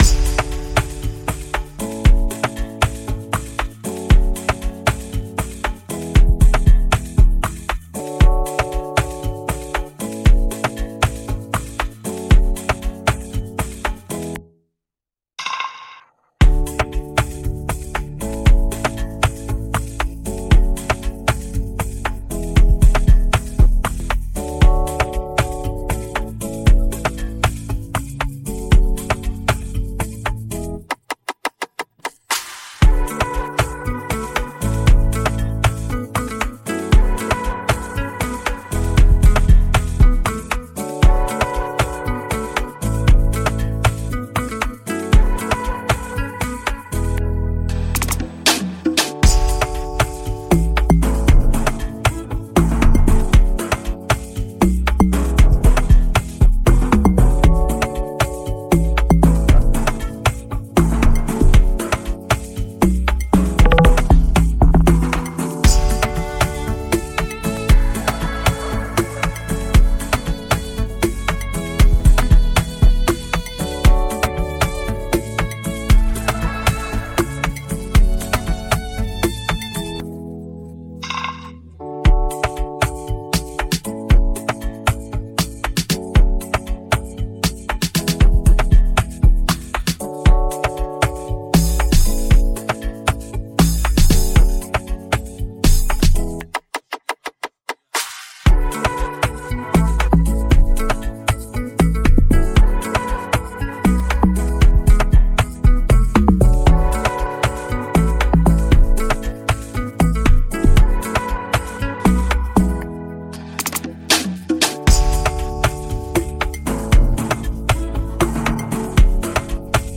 Afro dancehall
Tagged afrobeats , amapiano